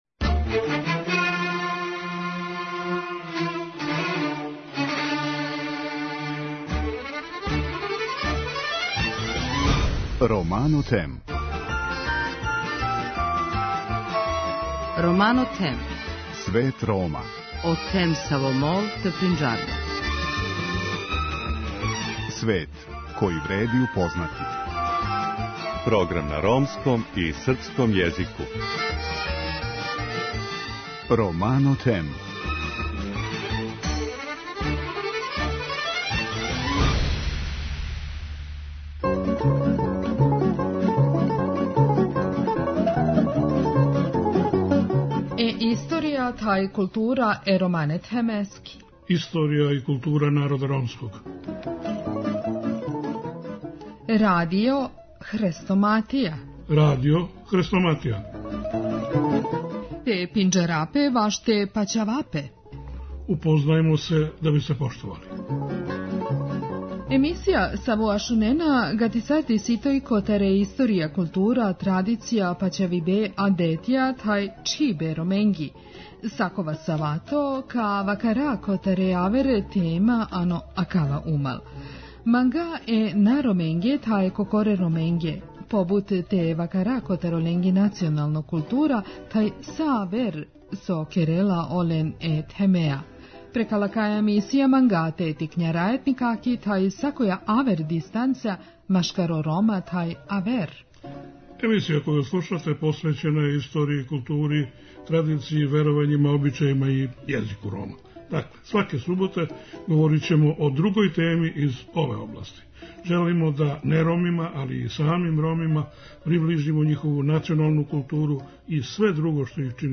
У вечерашњем издању слушаћете одломак дела „Кад сам био гарав" Мирослава Мике Антића. Мика Антић у књизи говори о раном детињству и дружењу с Милетом Петровићем, малим буљооким Циганином, кога су звали Миле Глупави или, како се то на циганском каже, Миле Дилеја.